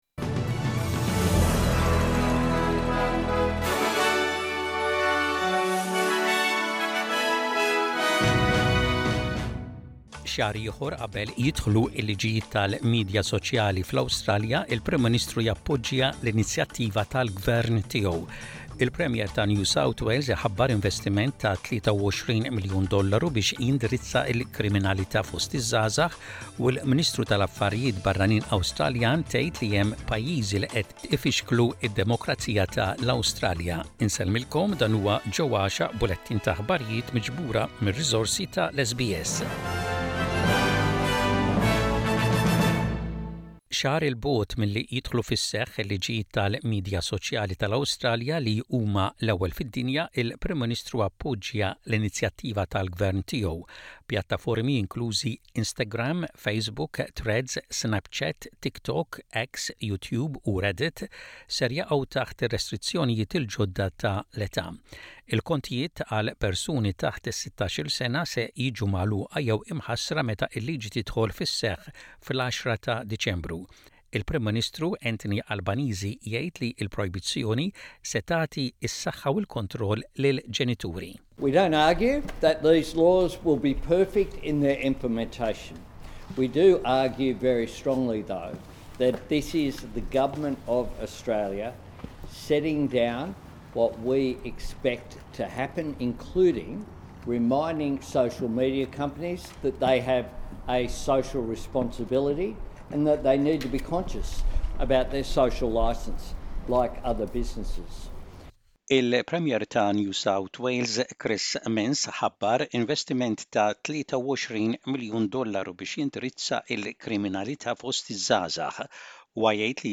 SBS Maltese News - Image SBS Maltese